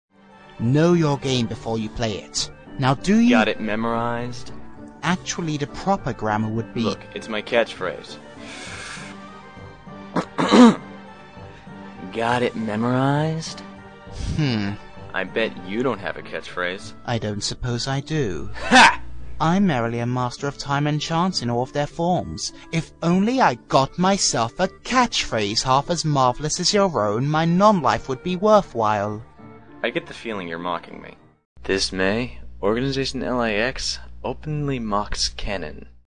We are an Organization-centric audio drama group who love producing crackish comedies for general consumption by other KH fans. This May, we are going to start releasing our work on a wide scale and just to whet your pallet, we have a few samples in the form of these swanky commercials.